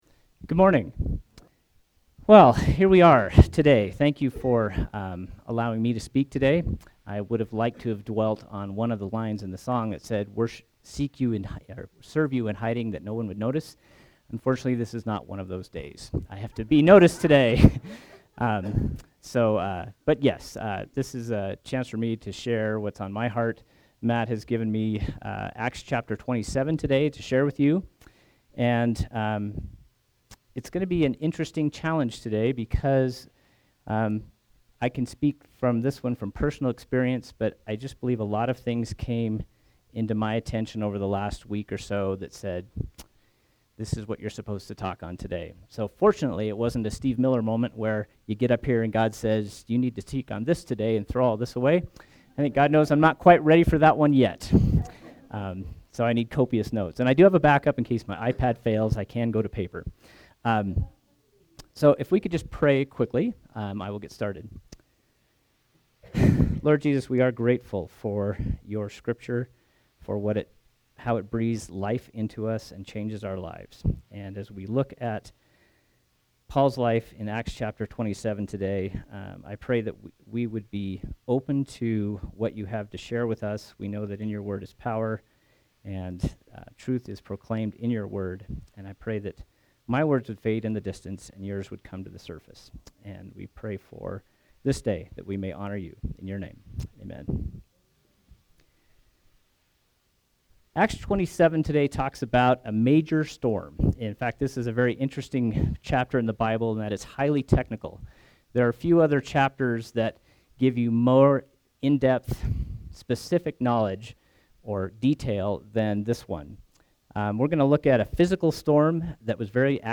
SERMON: Paul in the storm – Church of the Resurrection